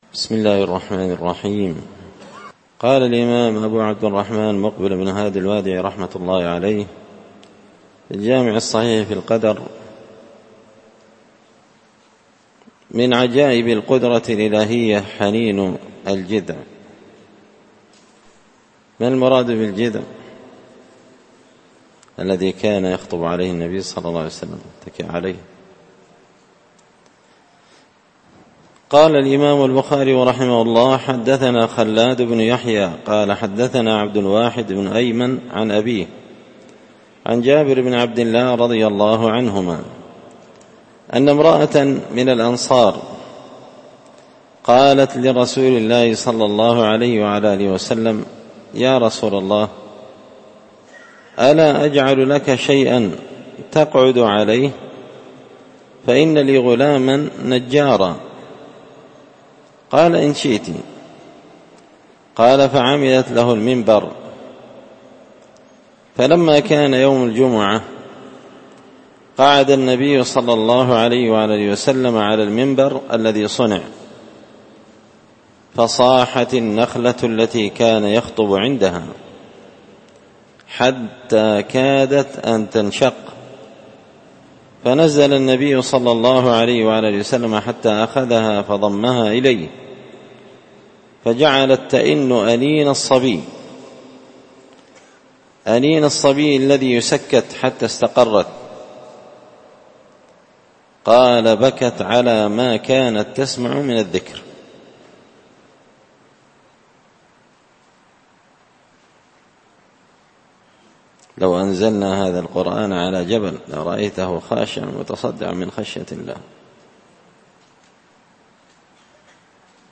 الدرس 120 فصل من عجائب قدرة الله الخارقة للعادة
دار الحديث بمسجد الفرقان ـ قشن ـ المهرة ـ اليمن